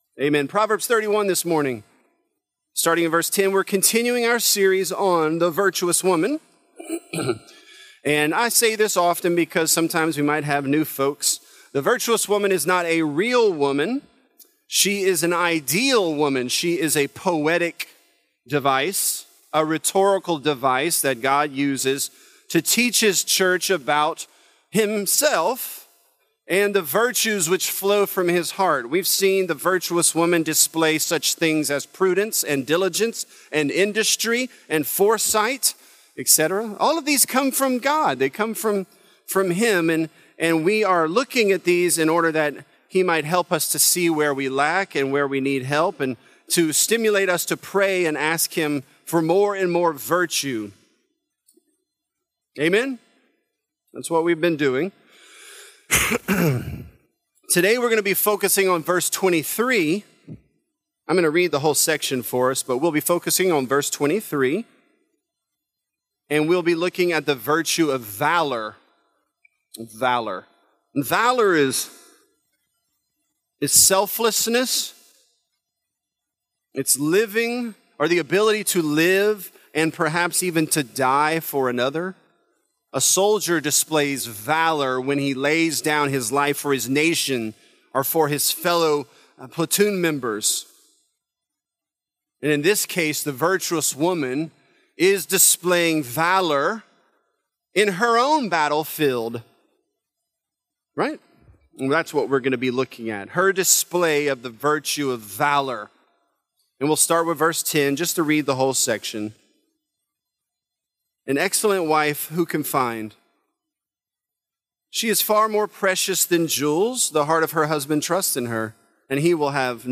This is a part of our sermon series, "Virtuous."